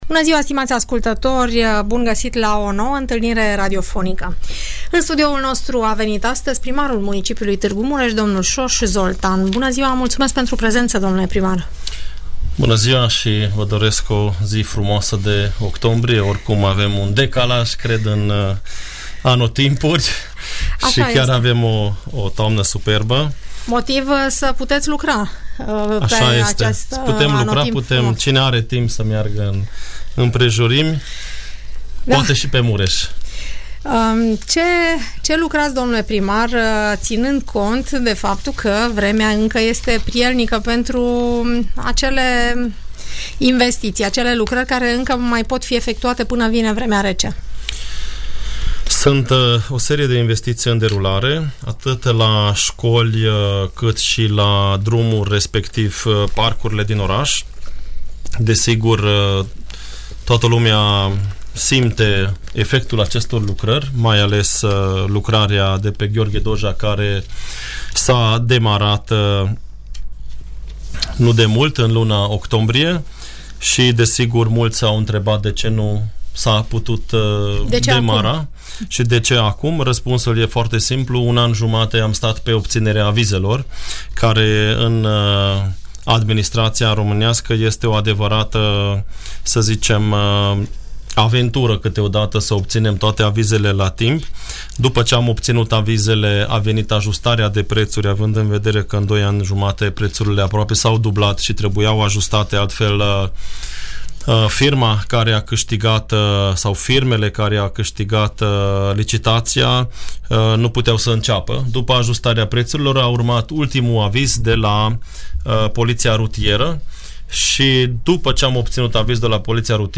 De vorbă cu primarul municipiului Tg Mureș